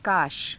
ふつう